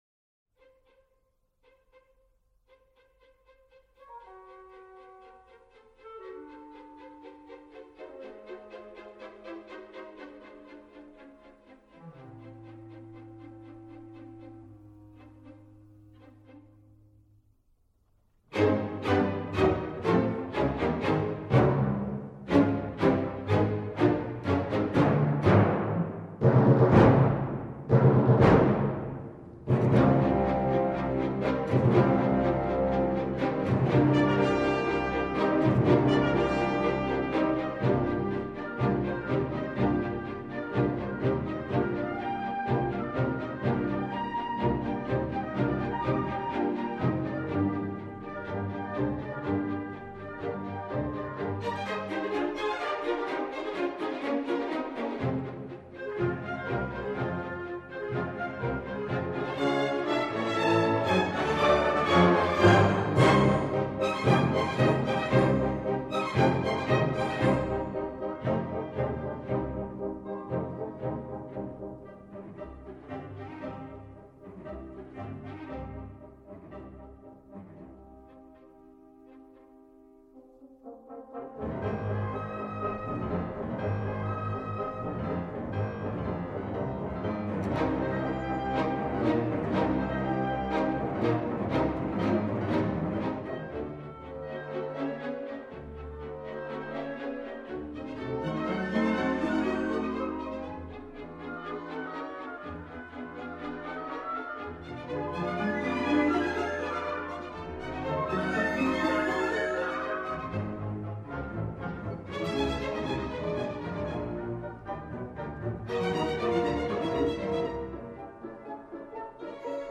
Marching Band Show - MP3 of cuts
marchingbandshowcuts.mp3